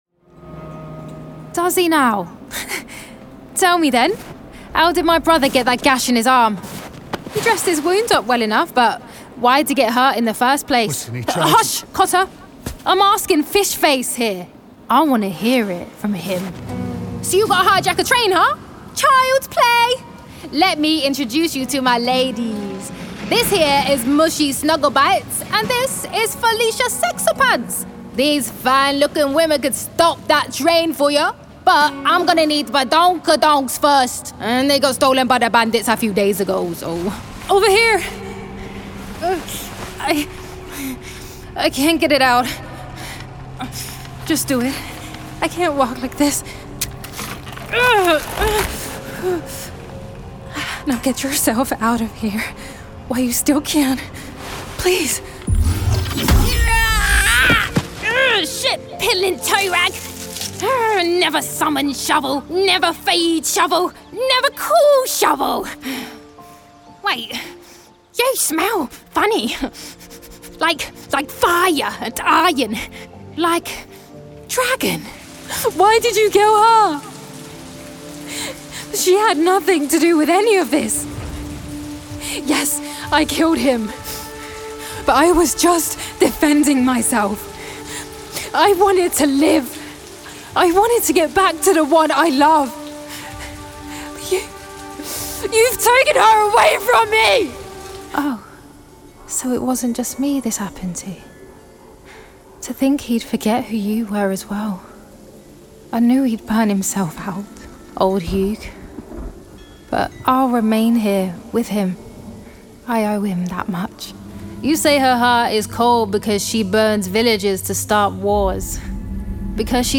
Gaming
Standard English/RP, London/Cockney, Caribbean, Northern (English), Eastern Europe - Bulgarian/Slovenian/Russian
Teens, Twenties
Actors/Actresses, Attitude, Modern/Youthful/Contemporary, Natural/Fresh, Quirky/Interesting/Unique, Character/Animation, Upbeat/Energy